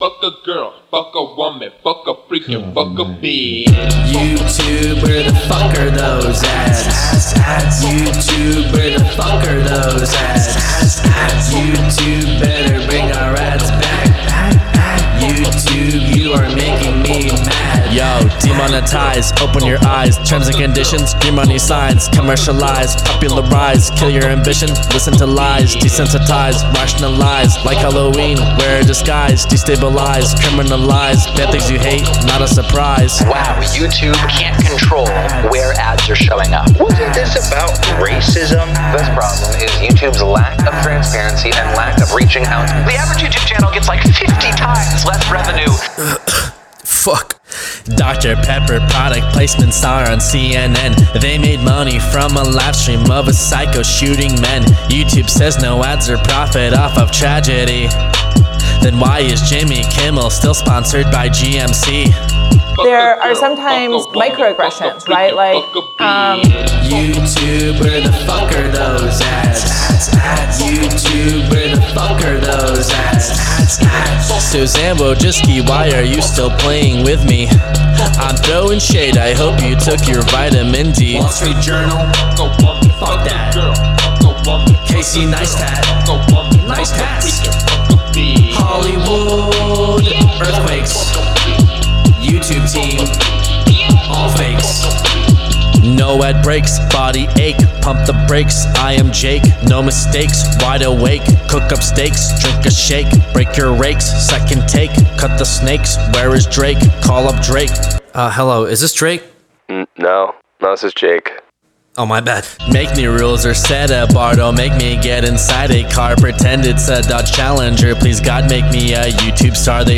BPM65-130
Audio QualityPerfect (High Quality)
trap song